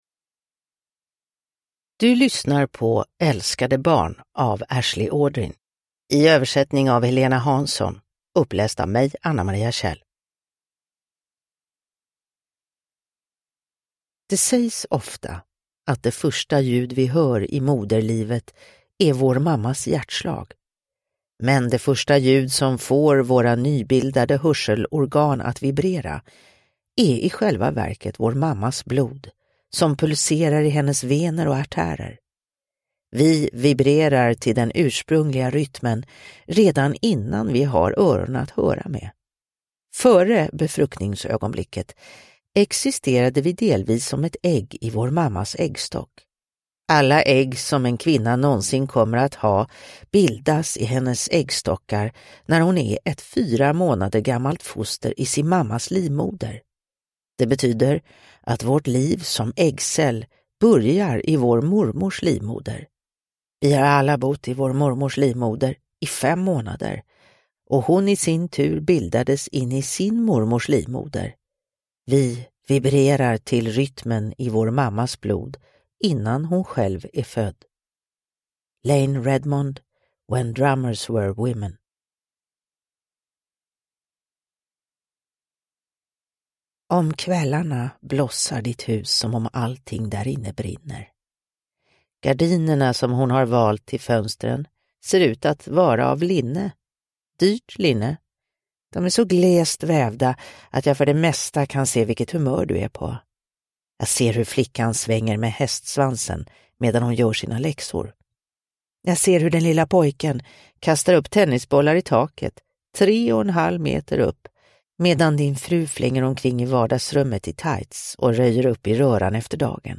Älskade barn – Ljudbok – Laddas ner